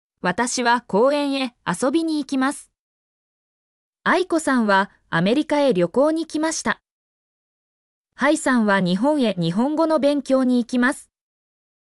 mp3-output-ttsfreedotcom-9_xaw3OEEv.mp3